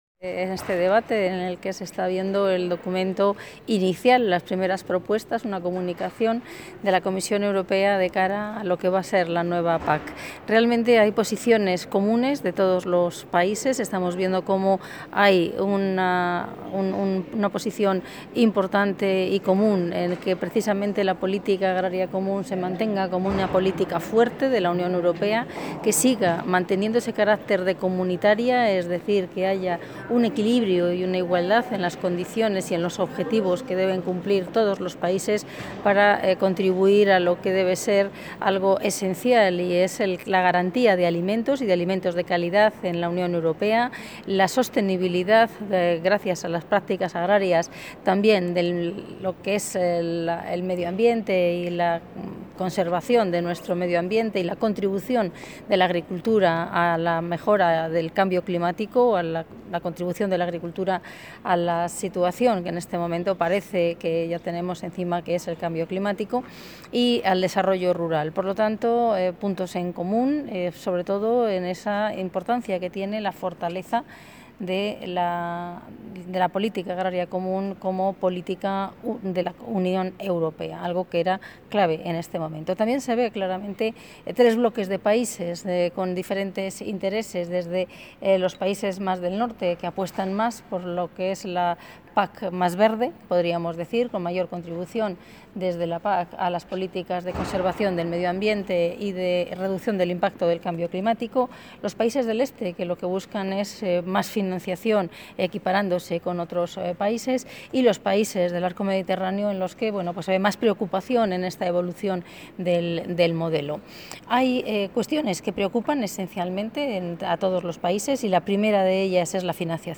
Declaraciones de la consejera de Agricultura y Ganadería.